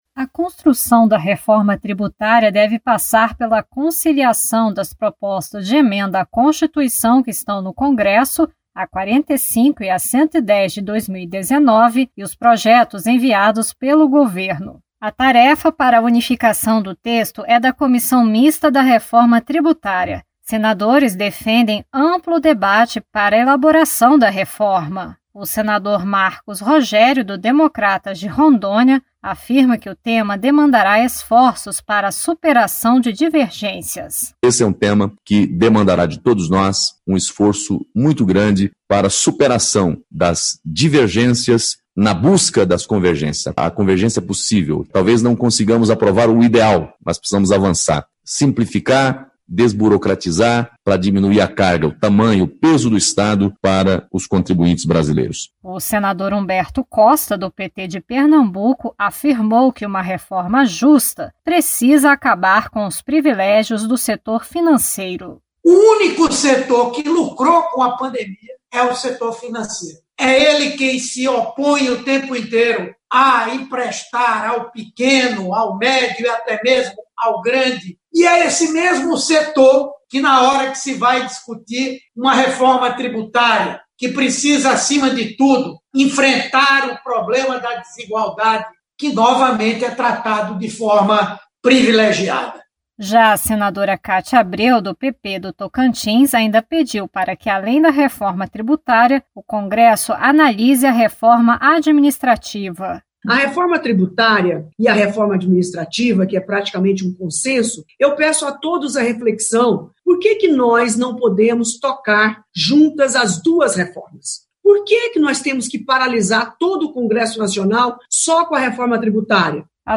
As propostas surgiram pela necessidade de simplificação do Sistema Tributário brasileiro e da diminuição da carga de impostos sobre a base do consumo. Acompanhe a reportagem